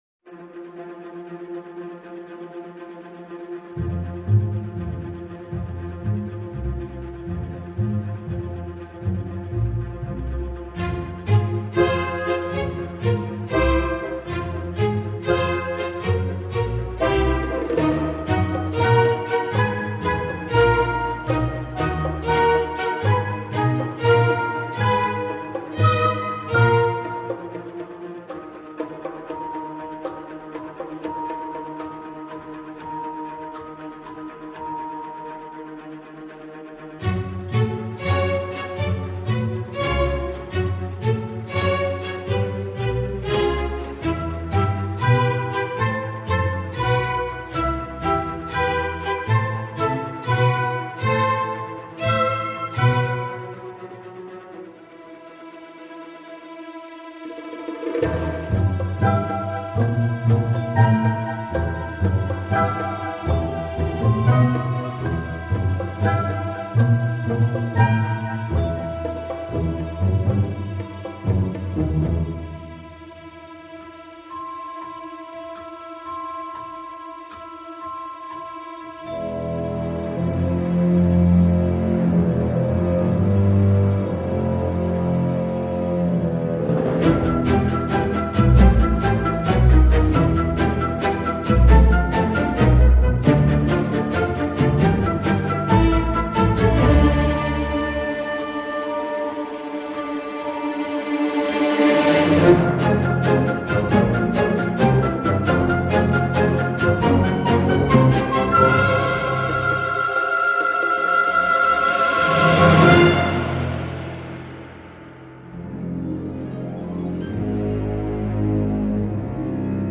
la fantaisie orchestrale et chorale
finit par trouver un fort plaisant rythme de croisière.